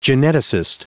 Prononciation du mot geneticist en anglais (fichier audio)
Prononciation du mot : geneticist